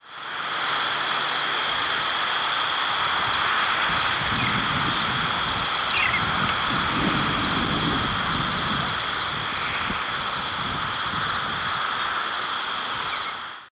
Field Sparrow diurnal flight calls
1. New Jersey November 23, 1997 (MO). Perched birds with American Crow calling in the background.